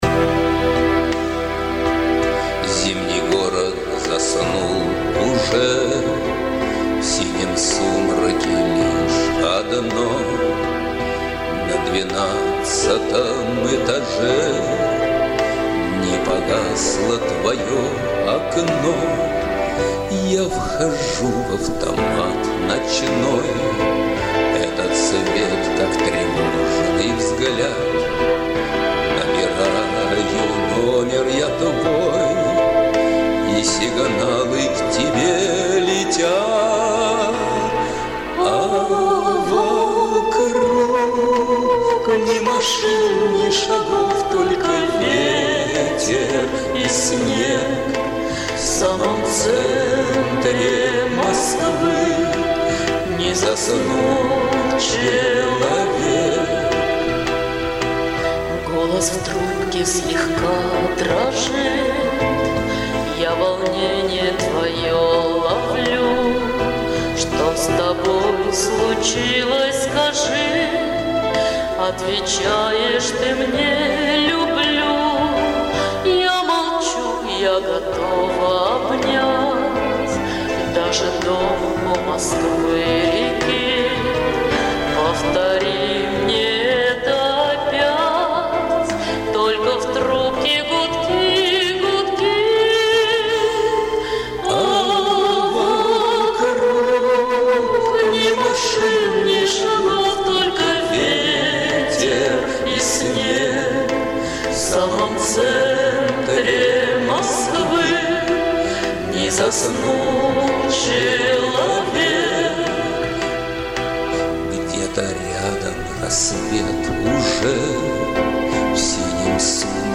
Качество записи у обоих, конечно, оставляет желать...